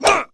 wrench_alt_fire2.wav